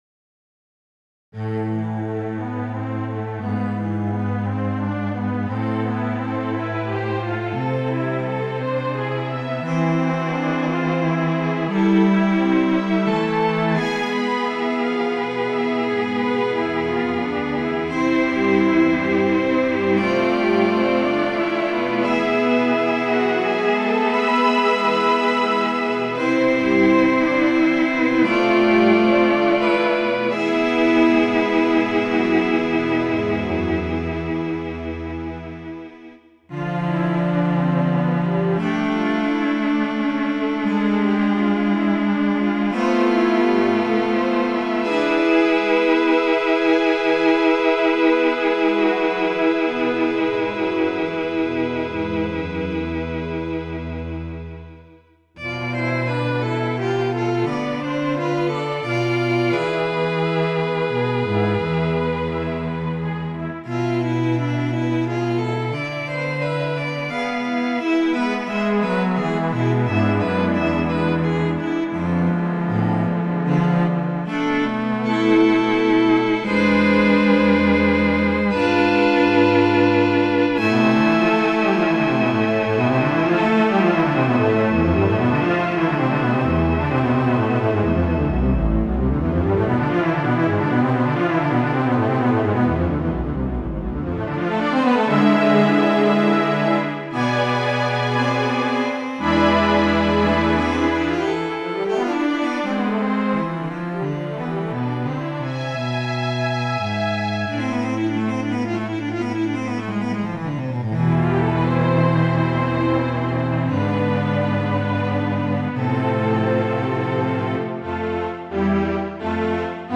クラシック　 ファイル名